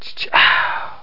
Ch Ch Oowww Instrument Sound Effect
Download a high-quality ch ch oowww instrument sound effect.
ch-ch-oowww-instrument.mp3